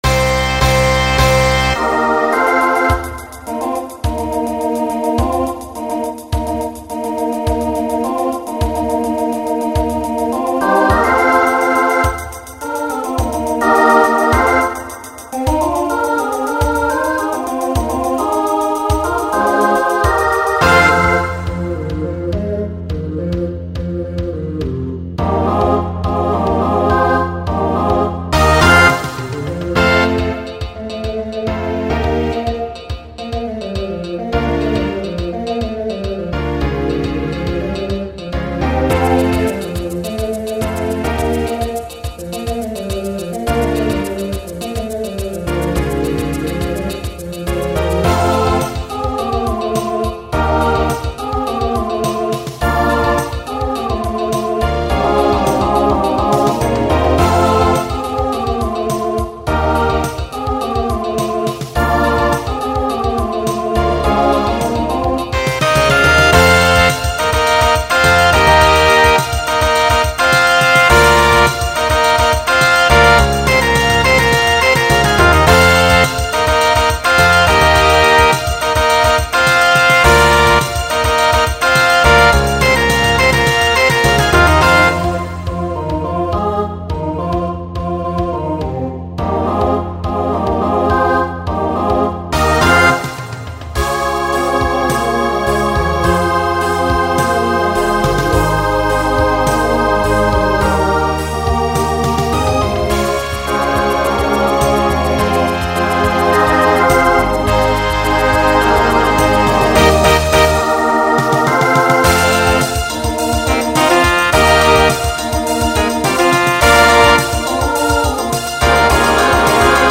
New SSA voicing for 2020